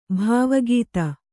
♪ bhāva gīta